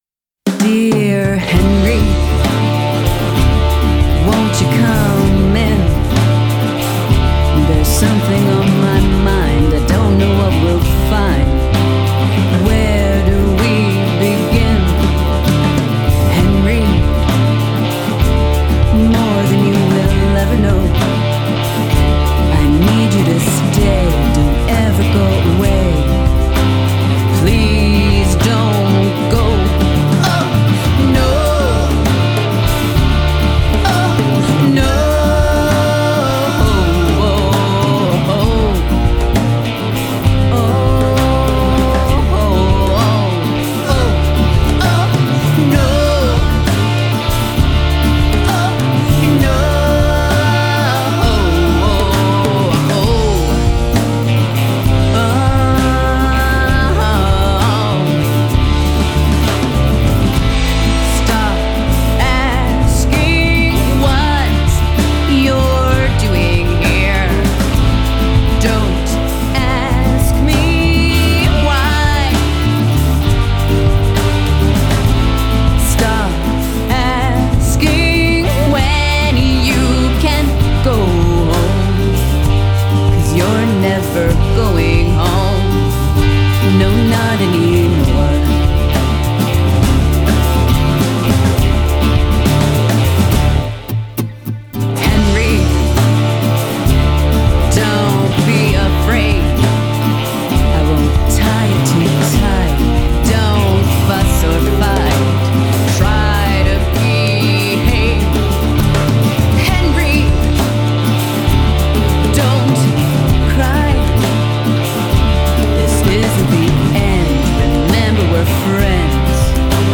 Genre: Pop, Rock